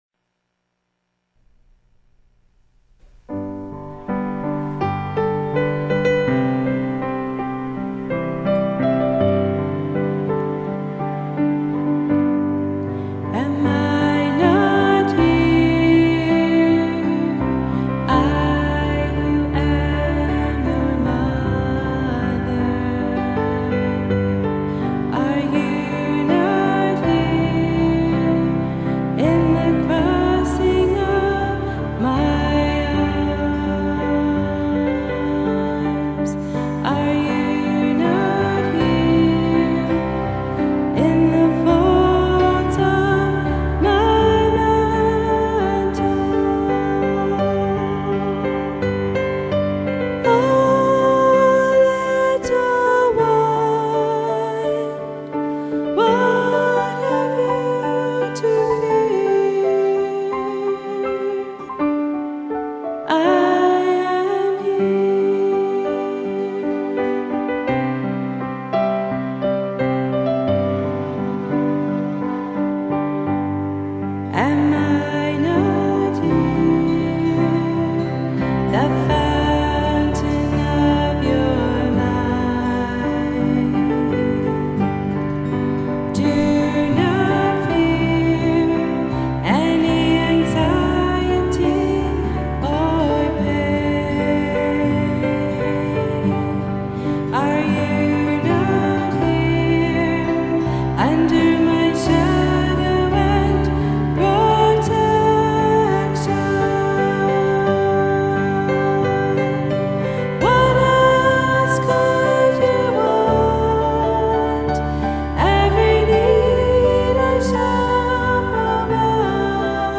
Take some time to listen to her beautiful words put to music by one of our sisters.